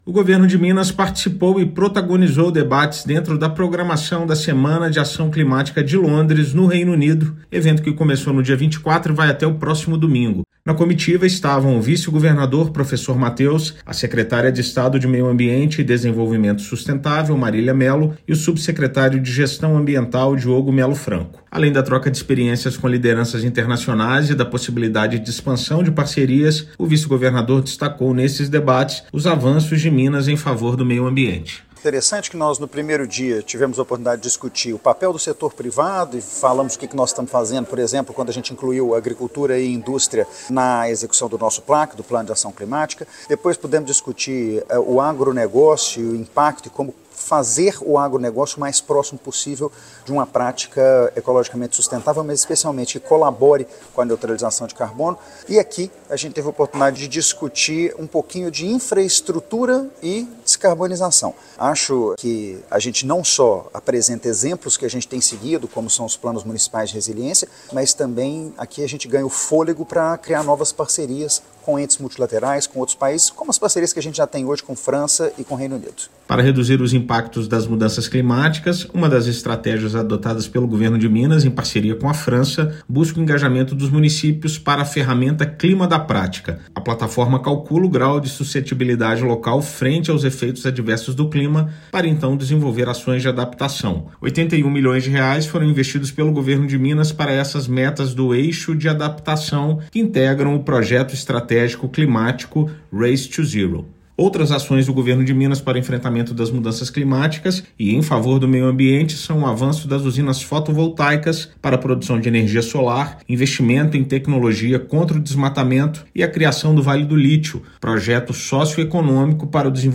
Em participação efetiva na Semana de Ação Climática de Londres, vice-governador mostrou resultados de Minas na campanha Race to Zero e discutiu soluções para a infraestrutura em momento de crise climática. Ouça matéria de rádio.